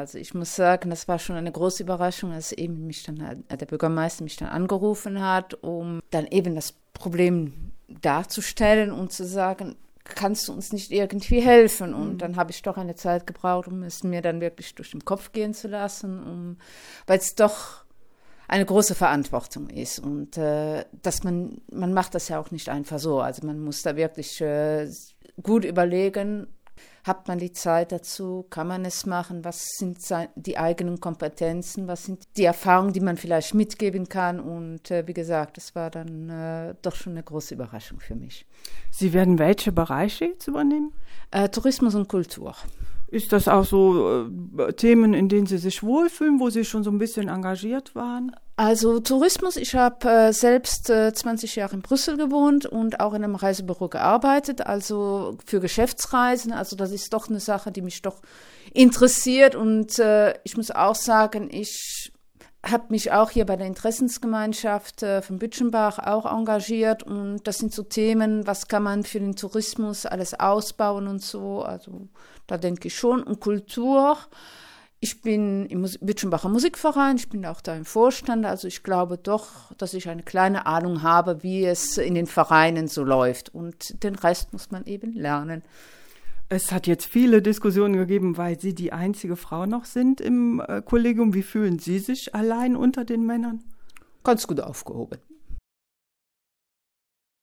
hat sich mit der neuen Schöffin unterhalten